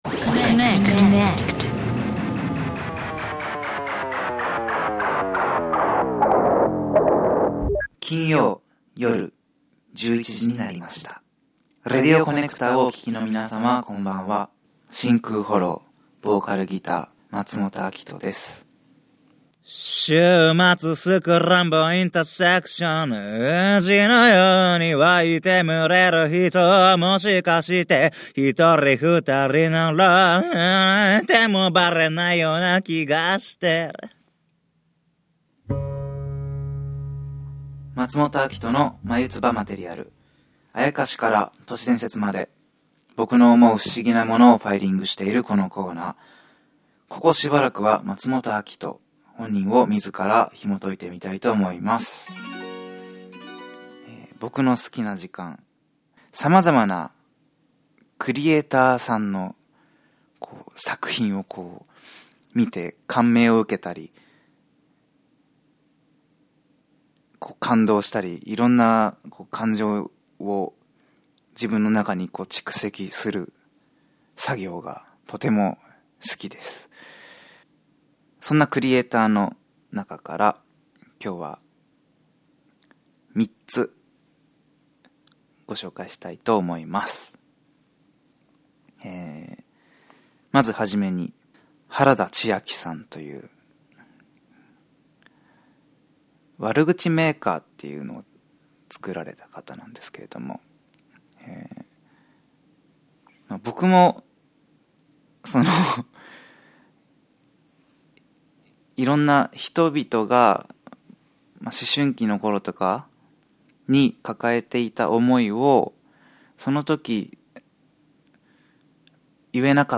ジシュクバージョンの♪「週末スクランブル」で唱。